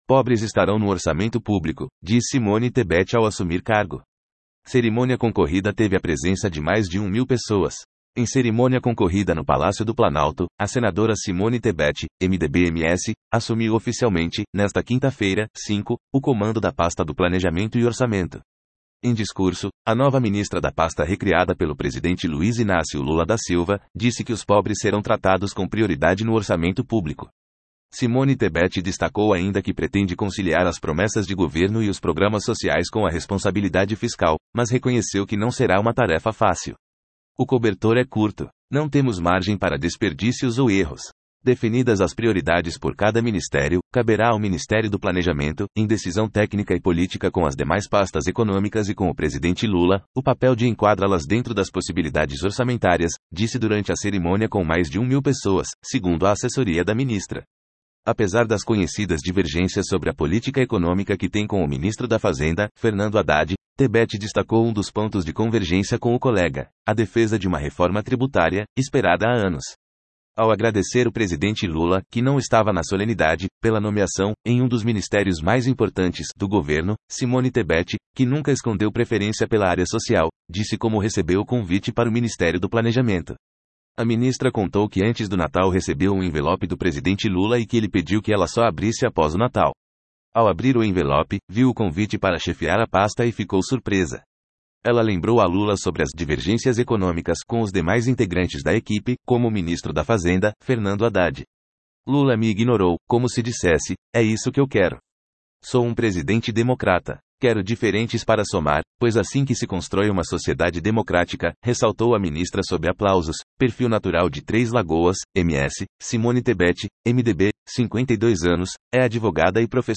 Cerimônia concorrida teve a presença de mais de 1 mil pessoas